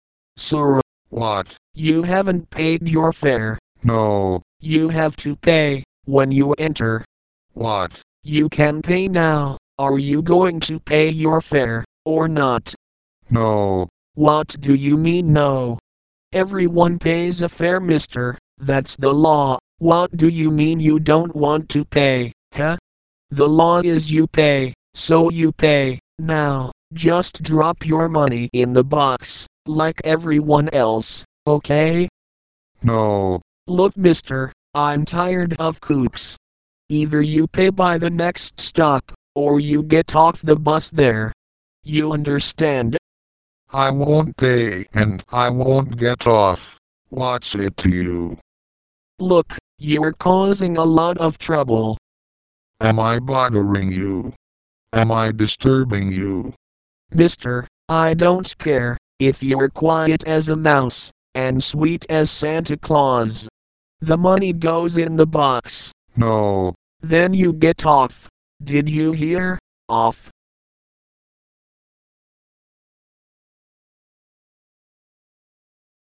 Conversaciones en un Autobús
Bus Driver and Rebel
conversation07.mp3